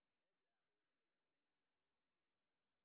sp08_street_snr0.wav